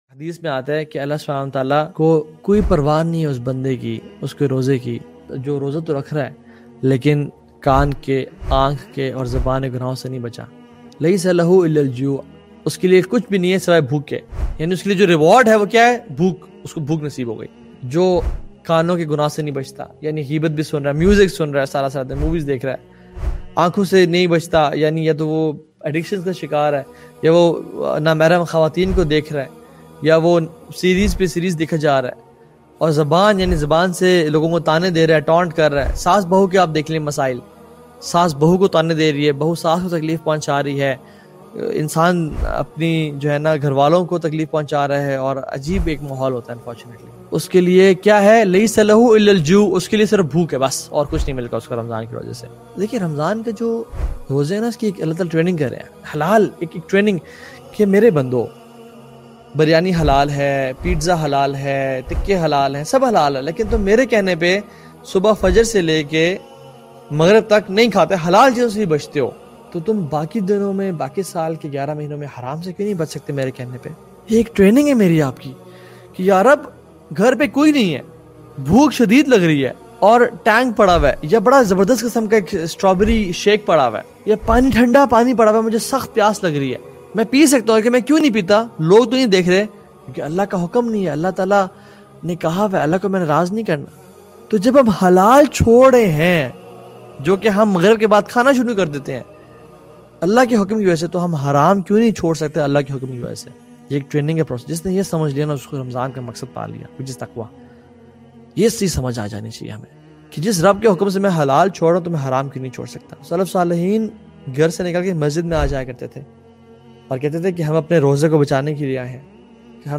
lectures